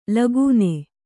♪ lagūne